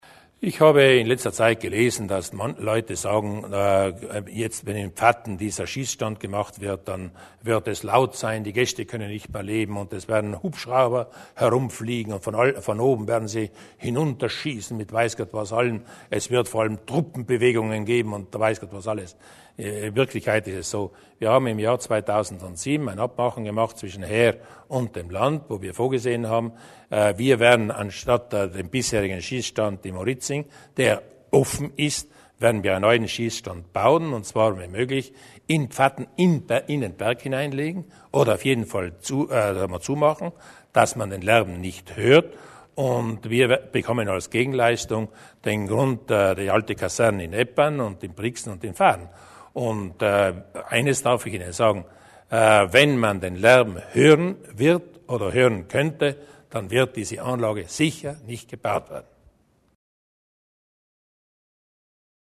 Landeshauptmann Durnwalder zum Schießstand in Pfatten